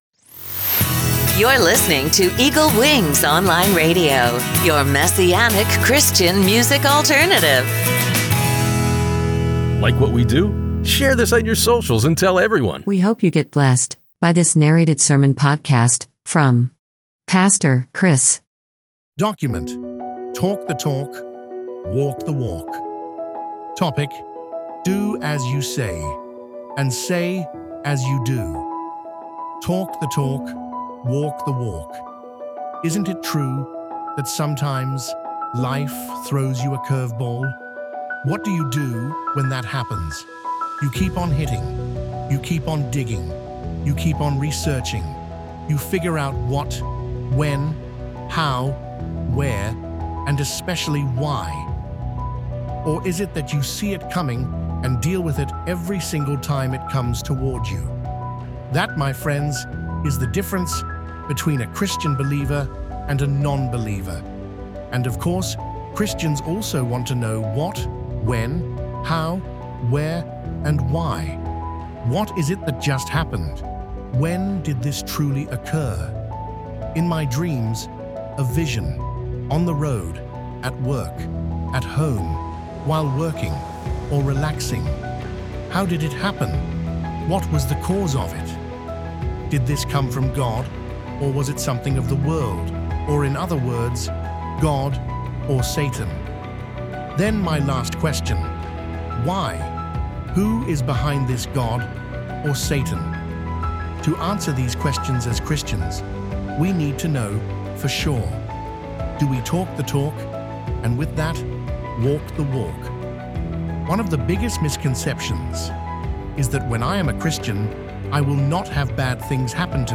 A Weekly Updated Narrated Message